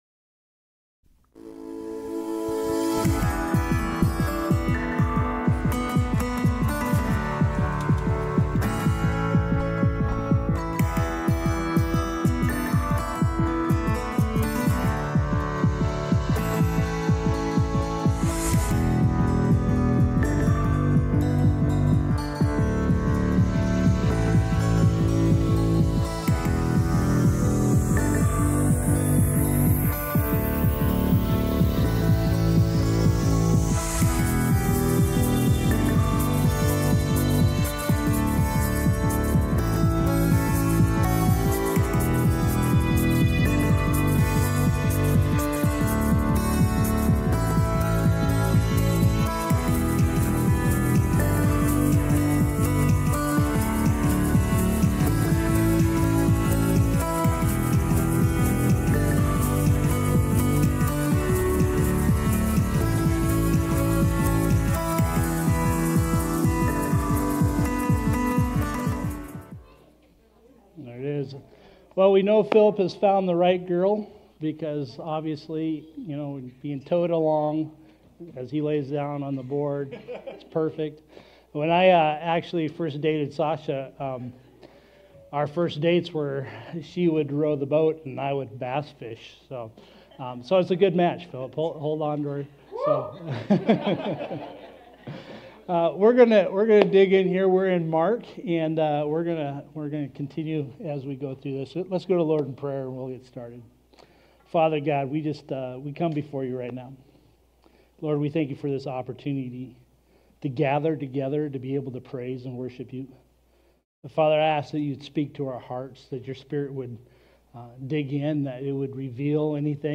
Sermons | Explore Church